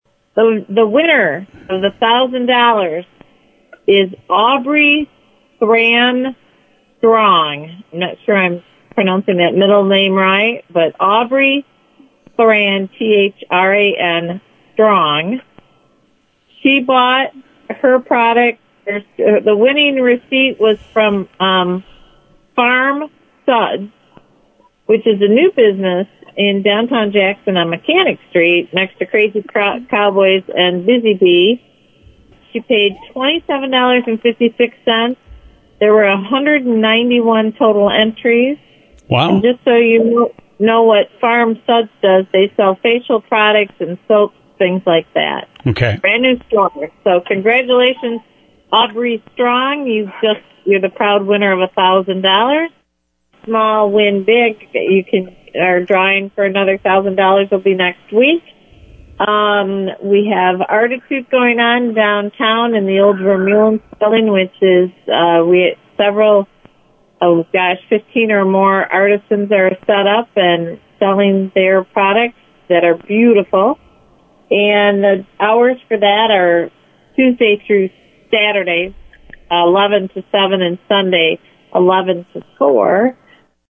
Another $1000 winner in the DDA’s ‘Shop Small, Win Big” promotion was announced Monday on WKHM